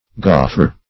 Gauffre \Gauf"fre\